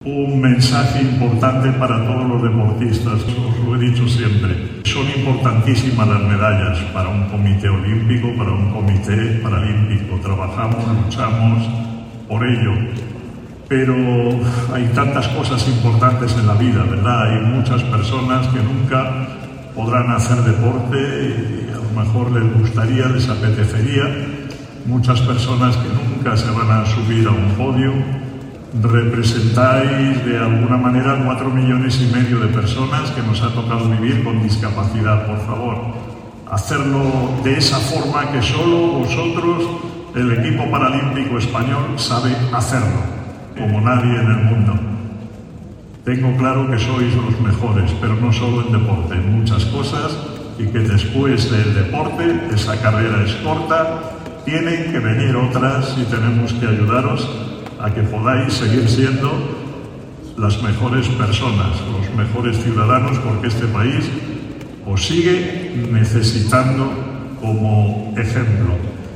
Justo un día antes de que la delegación paralímpica española viajara a París, autoridades, instituciones, representantes de las empresas patrocinadoras del Plan ADOP y medios de comunicación quisieron mostrar su apoyo y desear lo mejor a los miembros del Equipo Paralímpico Español, en un acto celebrado en la sede del Consejo Superior de Deportes (CSD), el pasado 23 de agosto.
Miguel Carballeda a los deportistas formato MP3 audio(0,64 MB) durante su intervención.